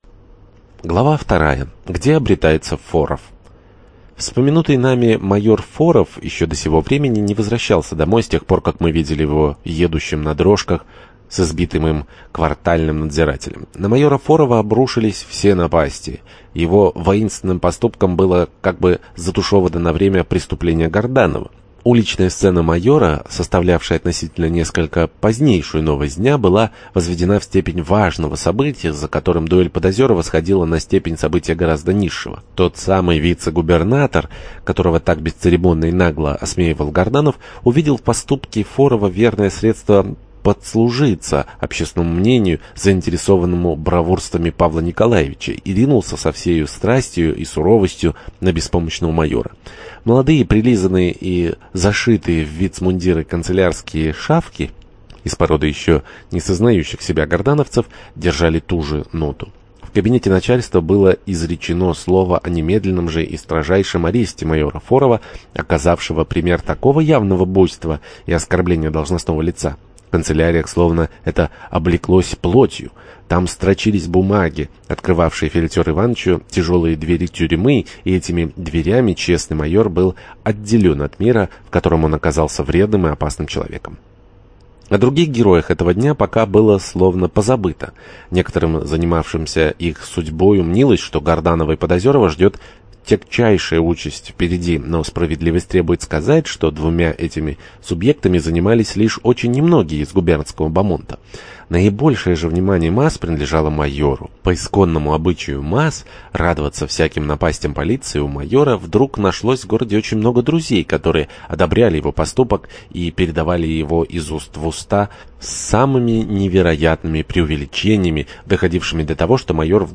Аудиокнига На ножах | Библиотека аудиокниг